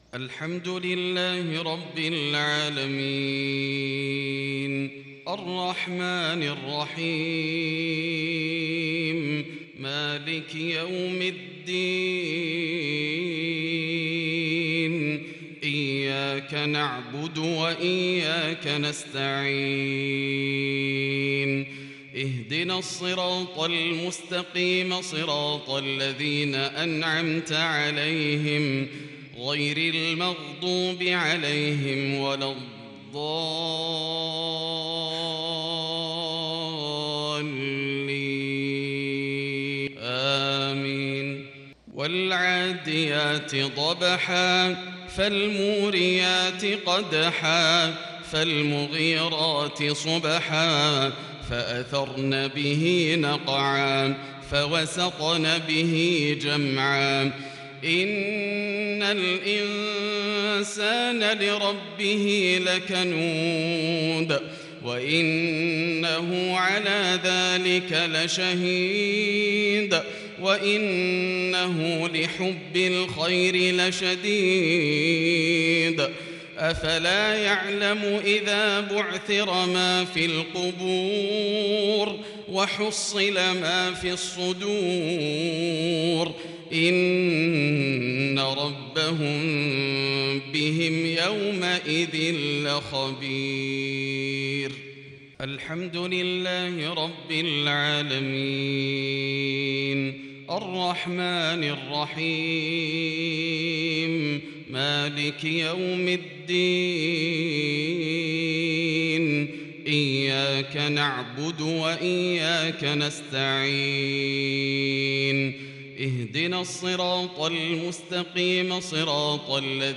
صلاة المغرب للشيخ ياسر الدوسري 1 ذو القعدة 1442 هـ
تِلَاوَات الْحَرَمَيْن .